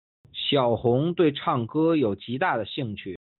小红对唱歌有极大的兴趣。\Xiǎo hóng duì chànggē yǒu jí dà de xìngqù.\Xiaohong tiene un gran interés por el canto.